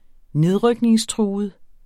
Udtale [ ˈneðʁœgneŋsˌtʁuːəð ]